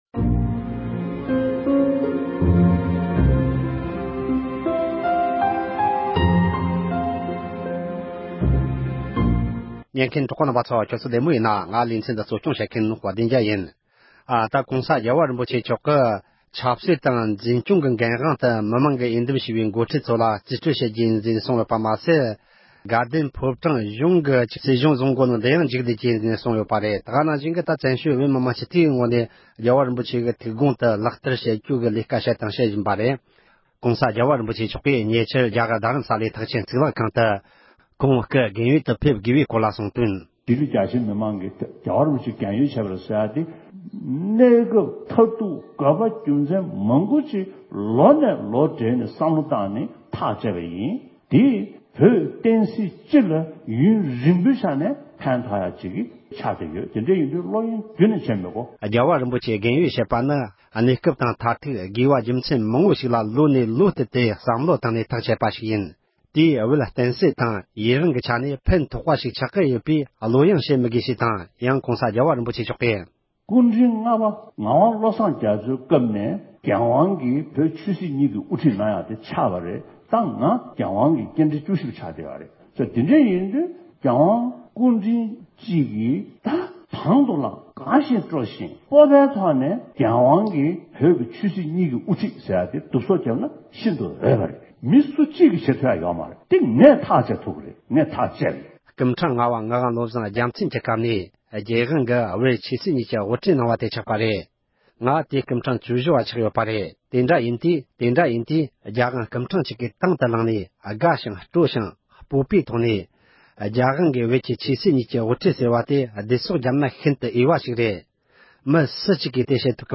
གླེང་མོལ་བྱས་པར་ཉན་རོགས་གནོངས།